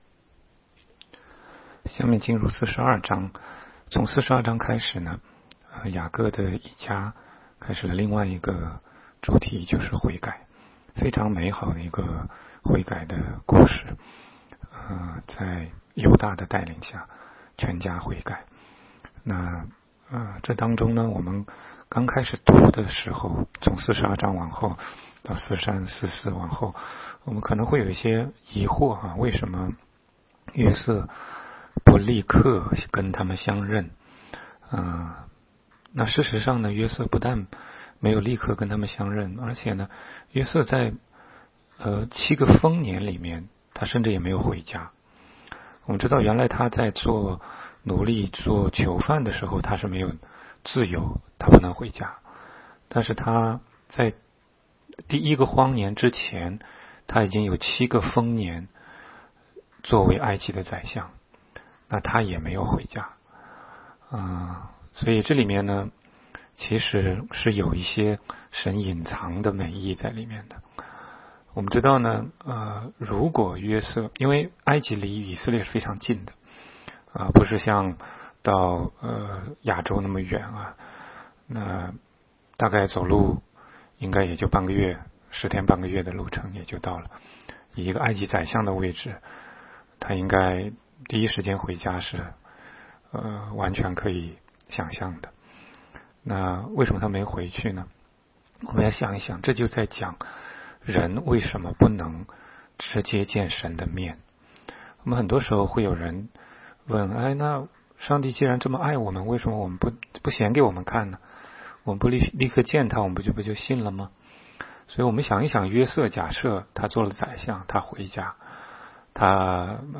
16街讲道录音 - 每日读经-《创世记》42章